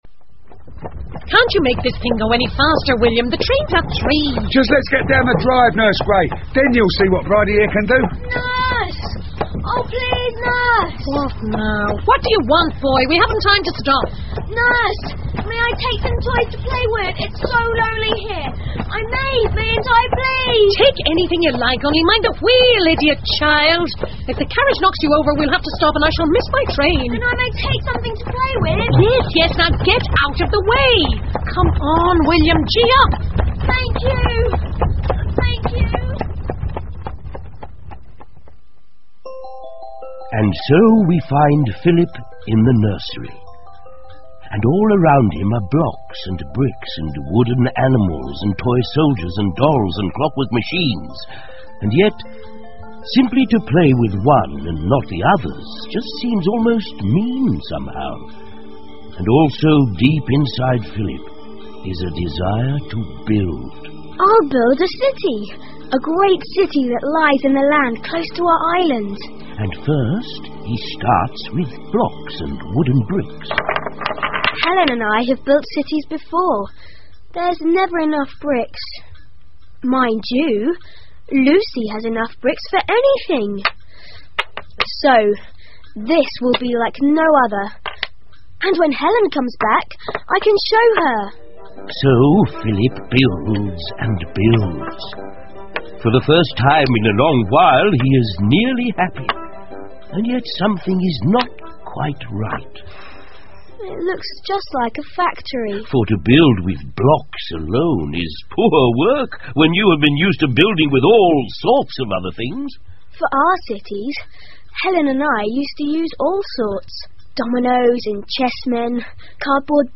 魔法之城 The Magic City by E Nesbit 儿童广播剧 3 听力文件下载—在线英语听力室